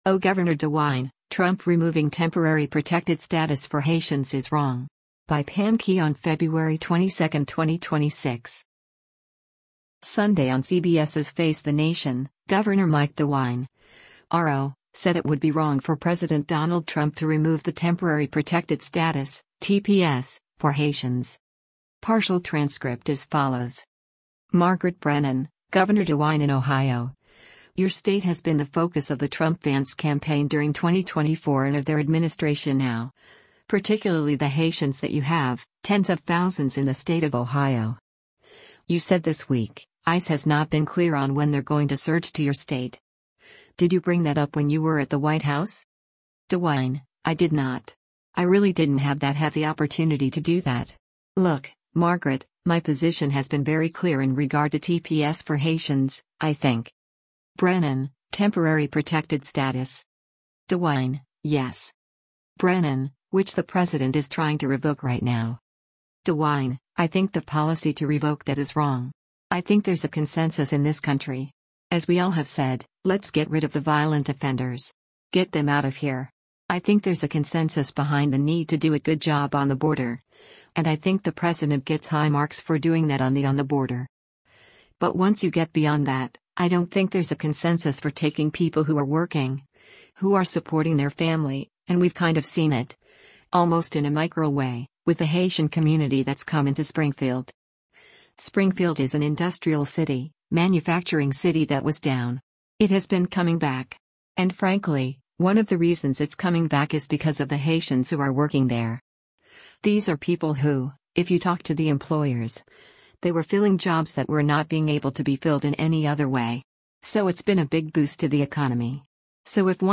Sunday on CBS’s “Face the Nation,” Gov. Mike DeWine (R-OH) said it would be “wrong” for President Donald Trump to remove the Temporary Protected Status (TPS) for Haitians.